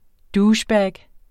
Udtale [ ˈduːɕˌbæːg ]